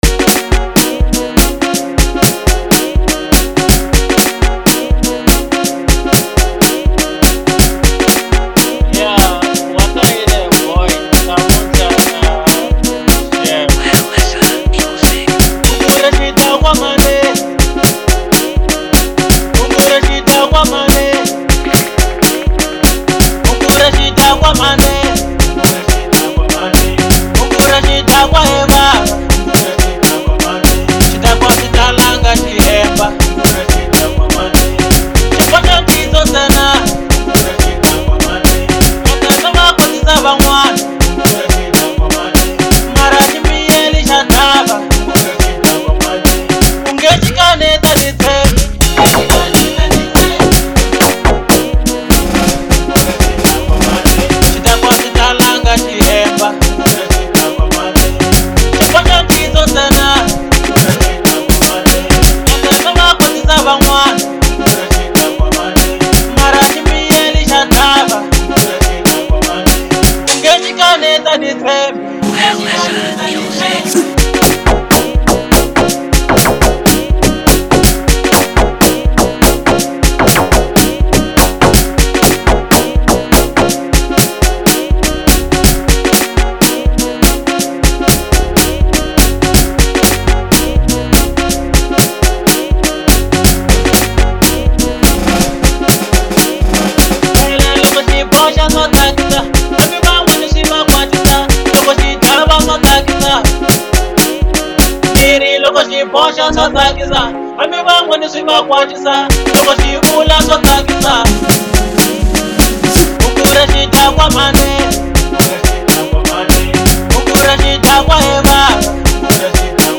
Local House